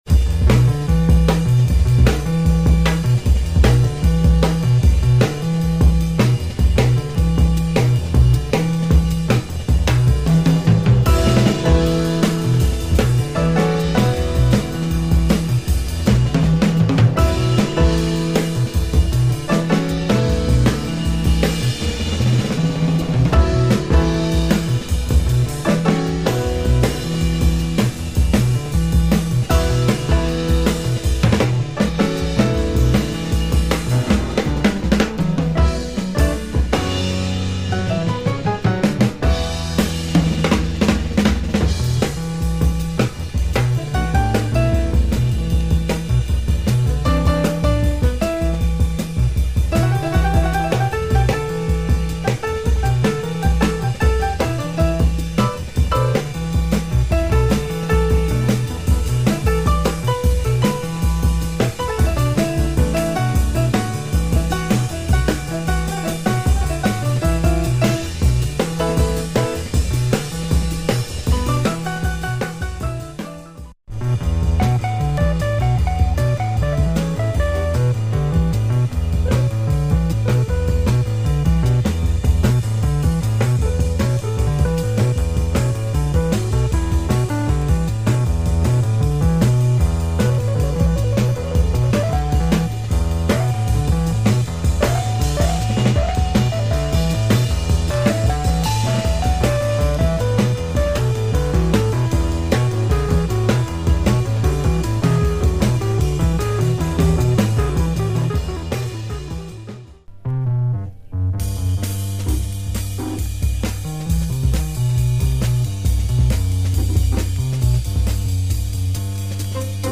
electric piano
drums
prog jazz